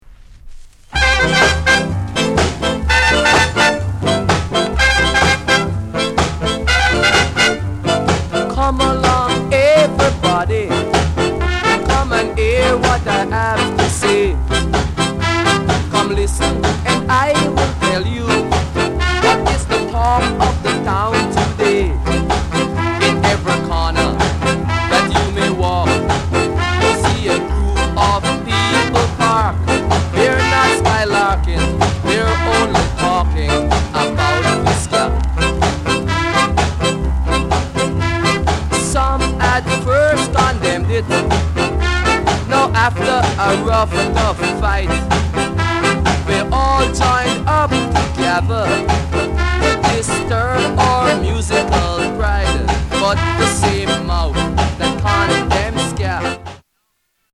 RARE SKA